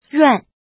ruàn
ruan4.mp3